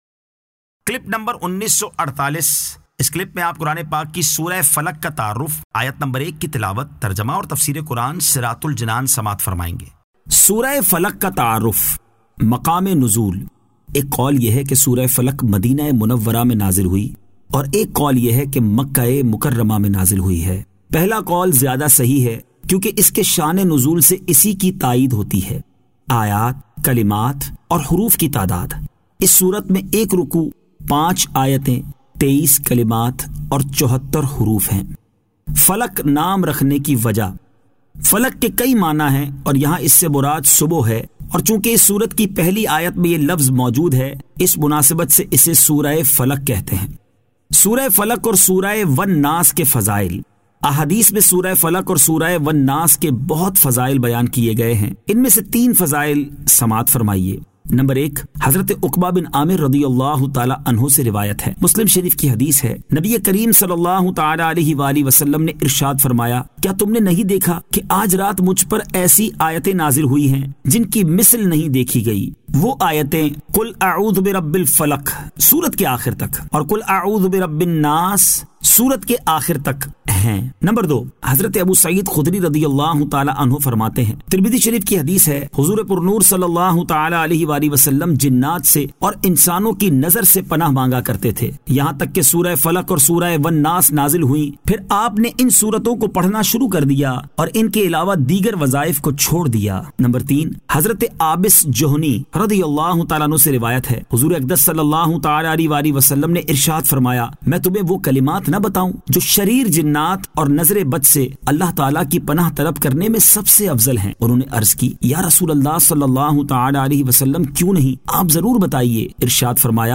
Surah Al-Falaq 01 To 01 Tilawat , Tarjama , Tafseer
2025 MP3 MP4 MP4 Share سُوَّرۃُ الْفَلَقْ آیت 01 تا 01 تلاوت ، ترجمہ ، تفسیر ۔